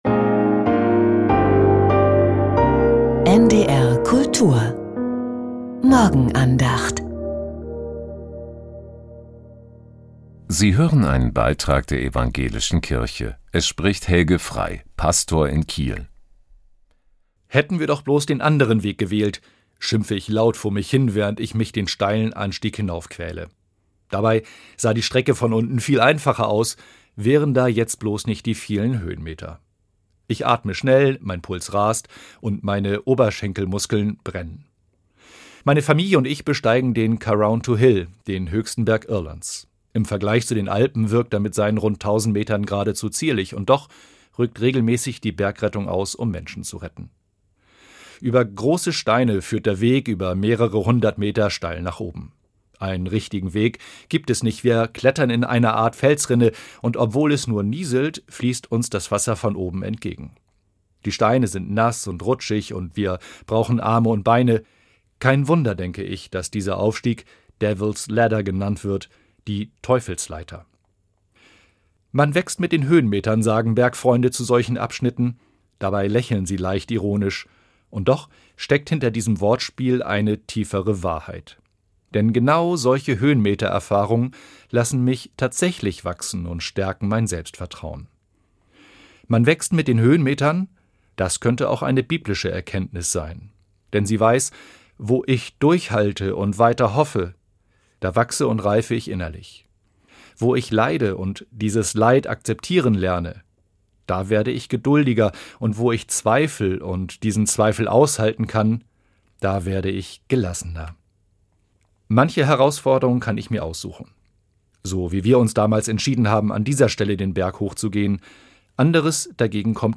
Die Andachten waren auf NDR Info und NDR Kultur zu hören.